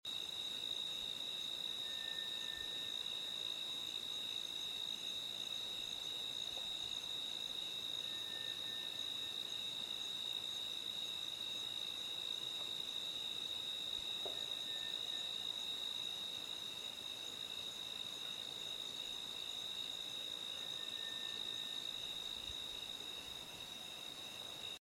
Parabolic Vocal Capture 10-7-11 Dropbox Vocal Capture 10-7-11 Below are several copies of a possible wood knock. It emanated from an area of an out of service Pump Jack that we had used as a listening/call broadcast post on Friday night. What you will hear is a loud knock type of sound, just after the last person has closed the door of their vehicle and begins to pull awasy.
This could still be a firearm discharge, although we doubt this as base camp did not report hearing it and we feel that they should have if it was gun fire given their distance to the pump jack was approximately 1200 yards.
box6_possible_knock.mp3